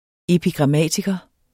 Udtale [ epigʁɑˈmæˀtigʌ ]